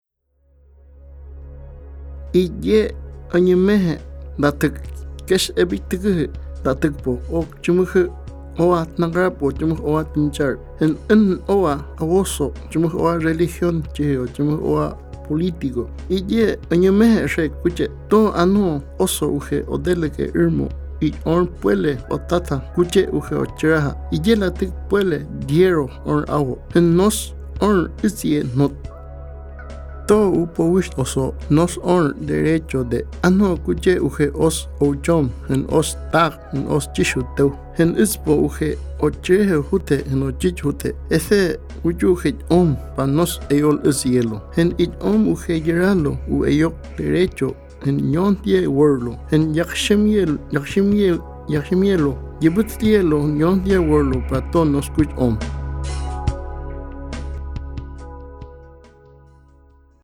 Spots Radiales